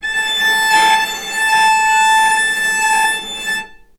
vc_sp-A5-ff.AIF